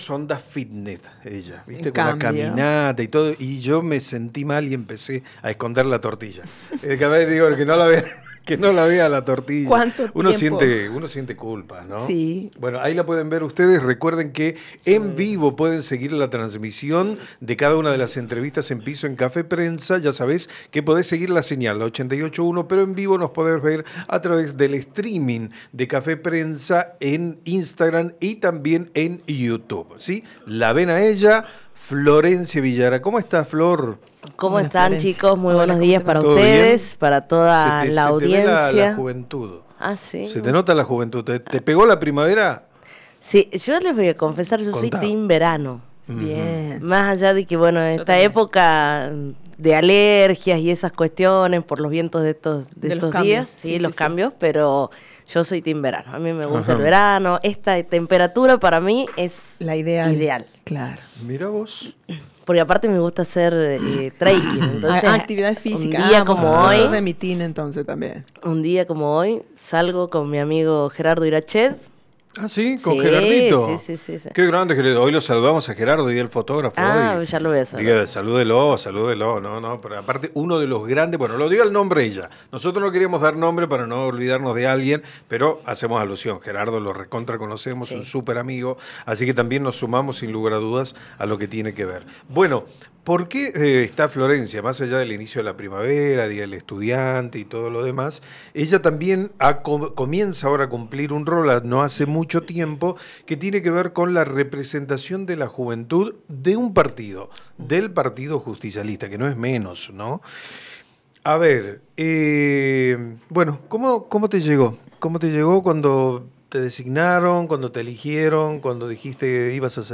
en diálogo con Café Prensa